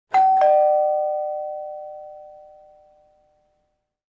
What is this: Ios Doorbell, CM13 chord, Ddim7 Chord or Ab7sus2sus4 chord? Ios Doorbell